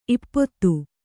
♪ ippottu